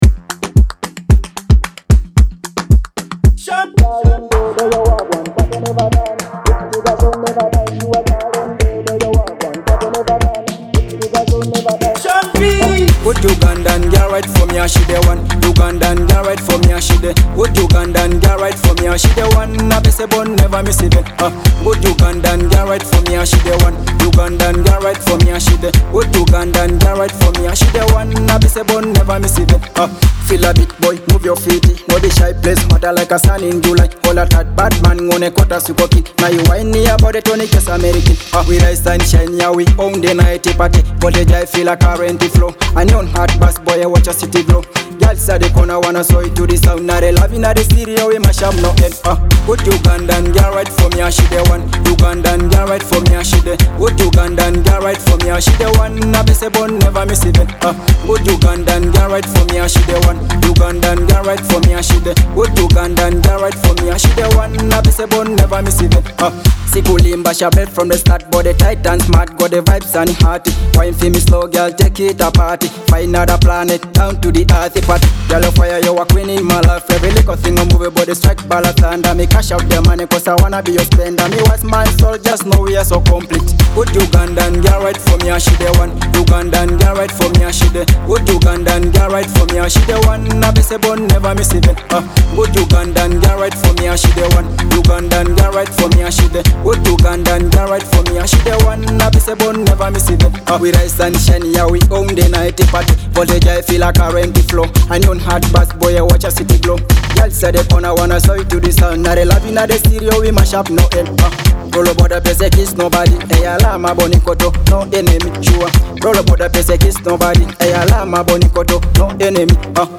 the ultimate dance-floor anthem